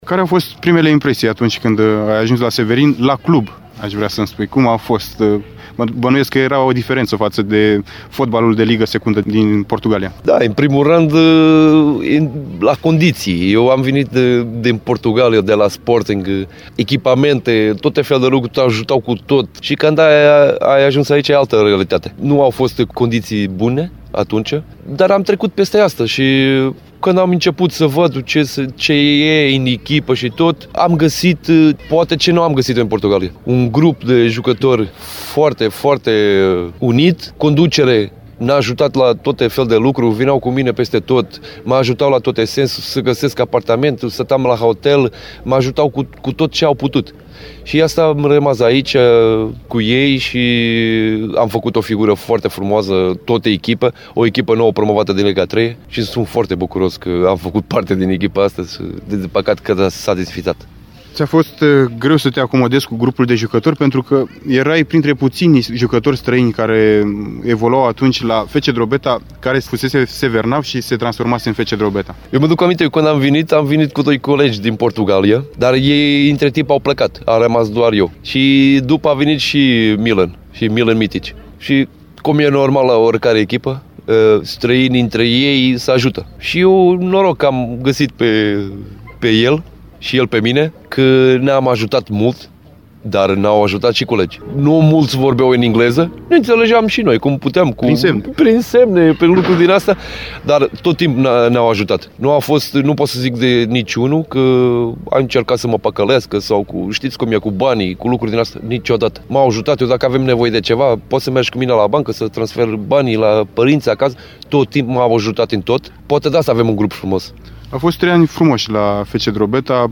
Al doilea episod al interviului